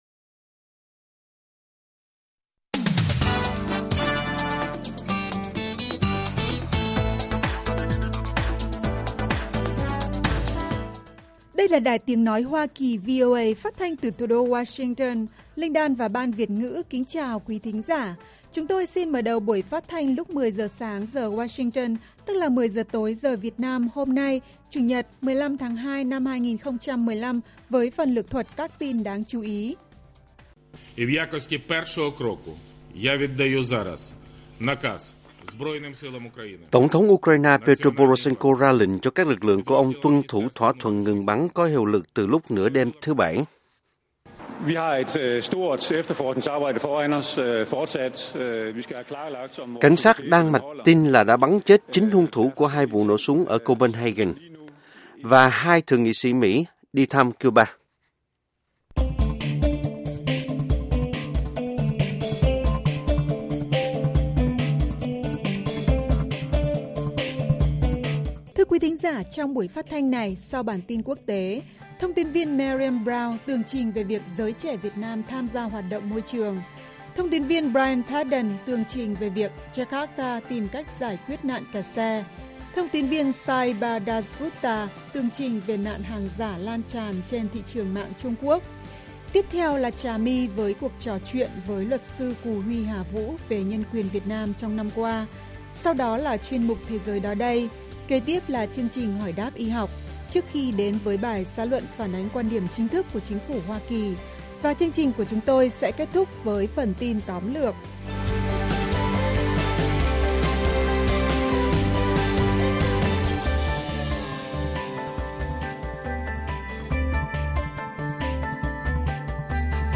Các bài phỏng vấn, tường trình của các phóng viên VOA về các vấn đề liên quan đến Việt Nam và quốc tế, và các bài học tiếng Anh.